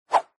window_open.mp3